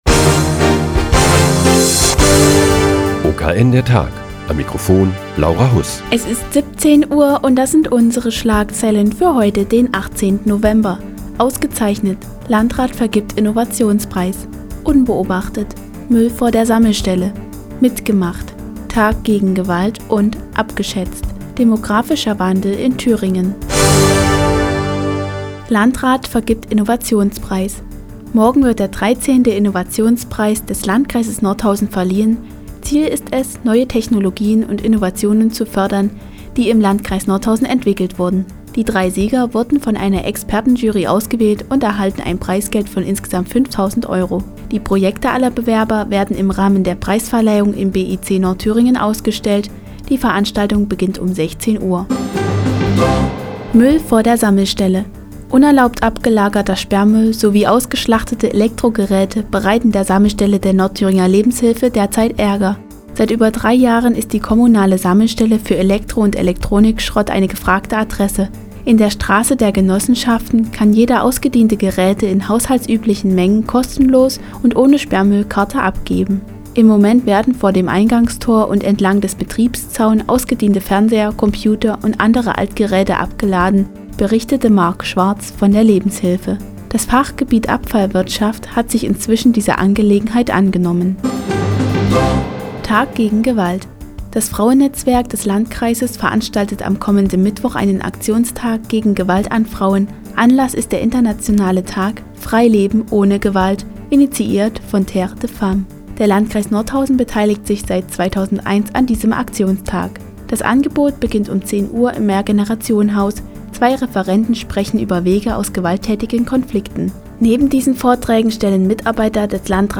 Die tägliche Nachrichtensendung des OKN ist nun auch in der nnz zu hören. Heute geht es um unerlaubt abgelagerten Sperrmüll und einen Aktionstag gegen Gewalt an Frauen.